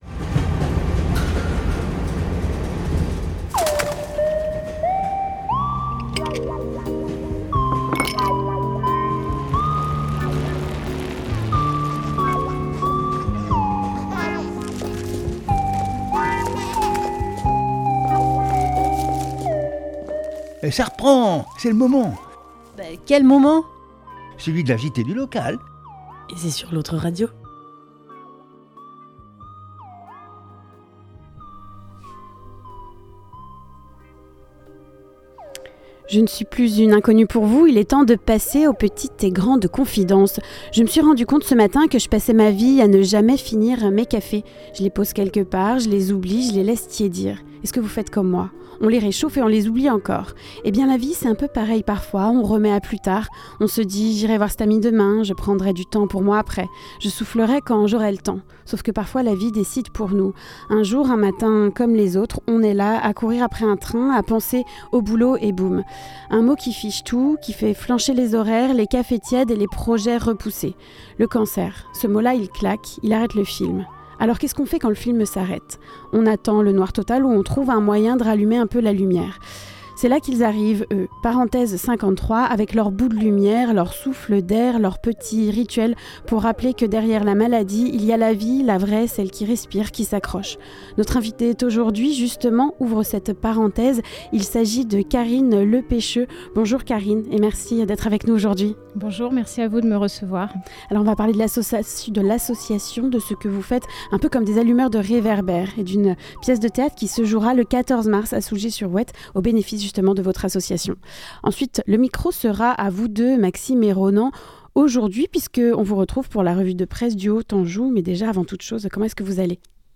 La revue de presse du Haut Anjou